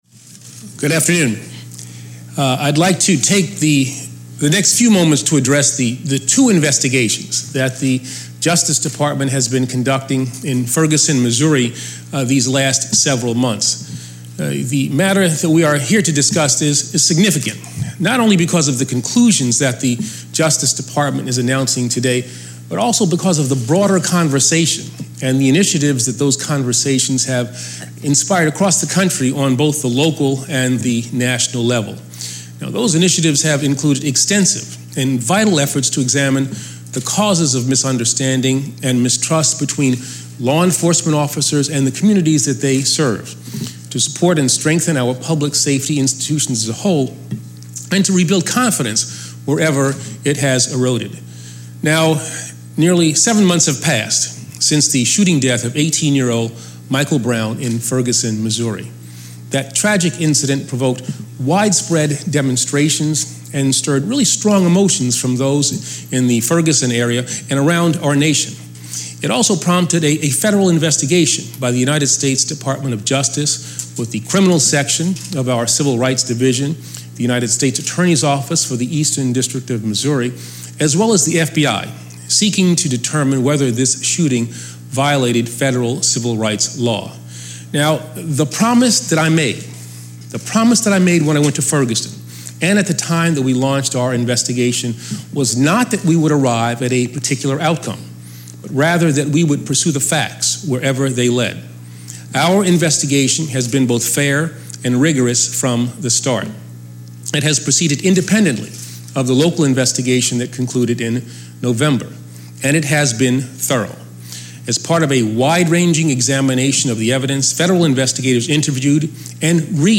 Eric Holder Address On Two Ferguson Police Department Investigations(transcript-audio-video)